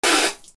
/ M｜他分類 / L30 ｜水音-その他
水を吐く
『ブッ』